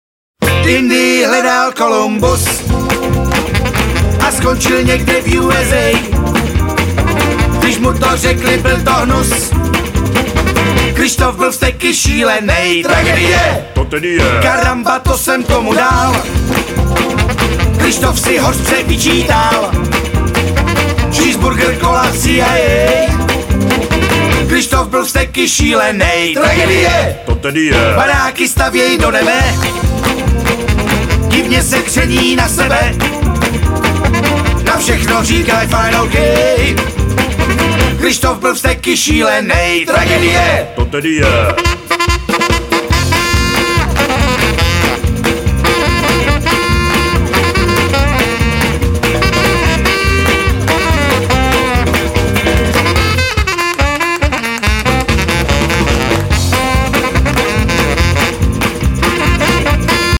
Rock’n’Roll!